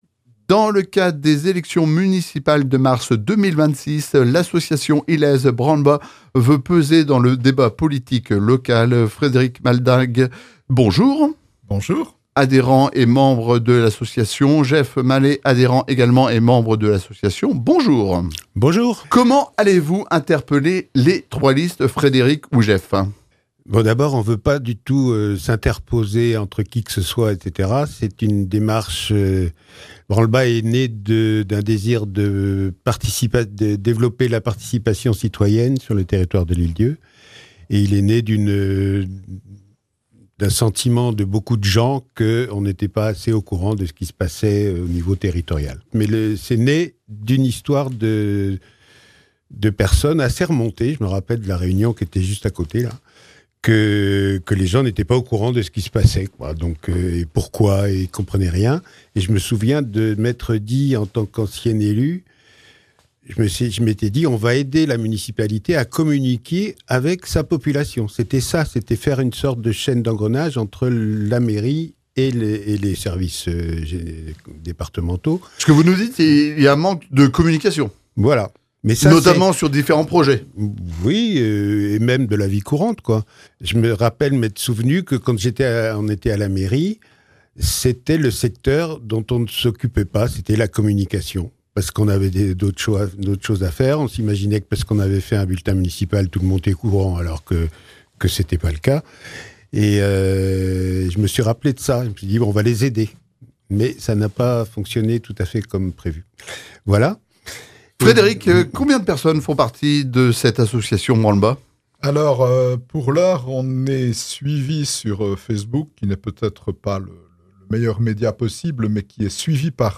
À moins de deux mois des élections municipales, nous recevons l’association Branlebas, représentée par deux de ses membres